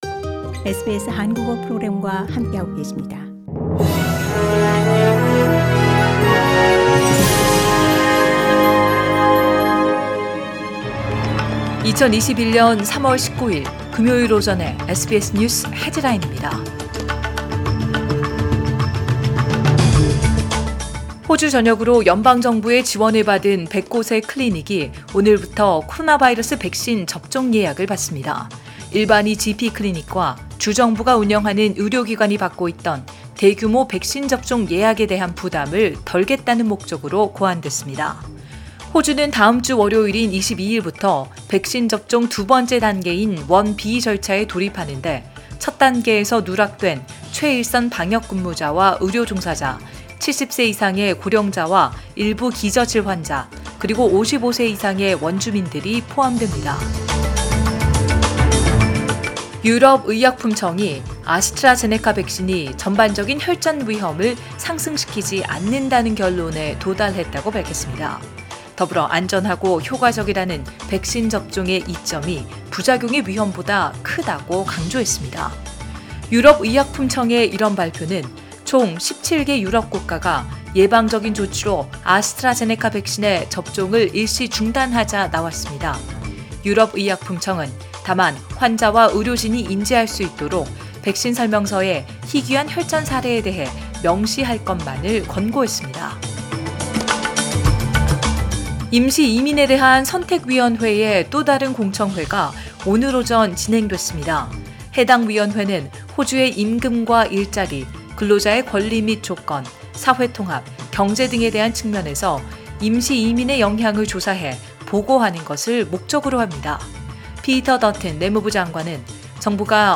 2021년 3월 19일 금요일 오전의 SBS 뉴스 헤드라인입니다.